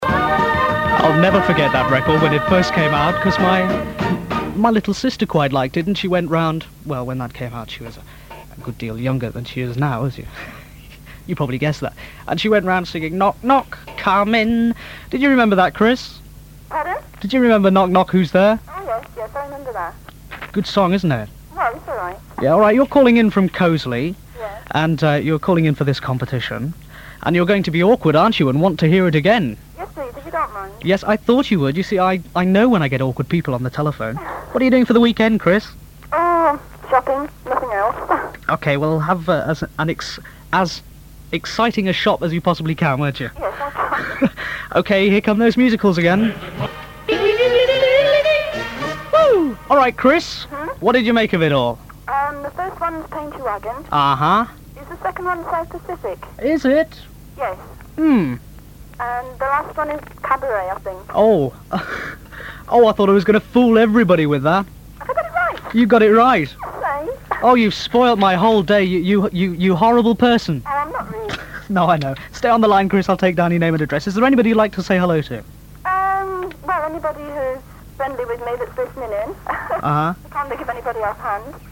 A clip from the early 80's from Beacon.